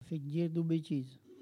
Mots Clé parole, oralité ;
Collectif atelier de patois
Catégorie Locution